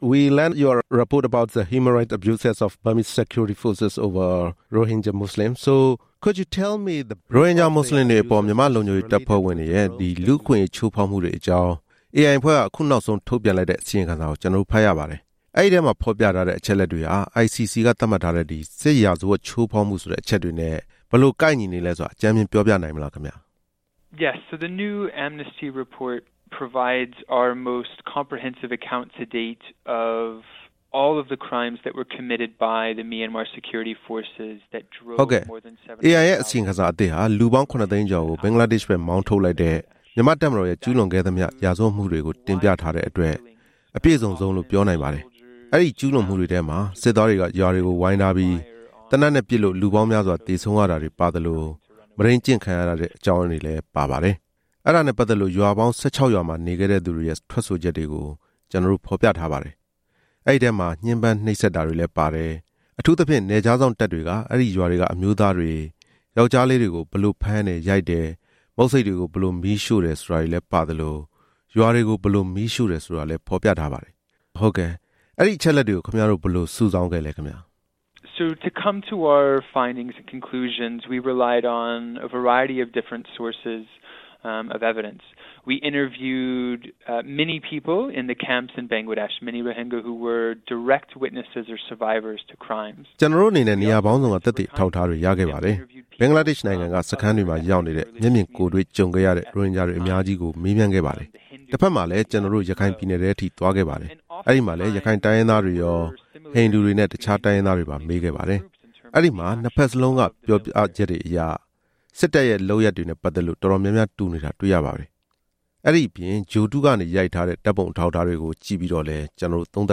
AI အစီအရင်ခံစာအကြောင်း ဆက်သွယ်မေးမြန်းချက်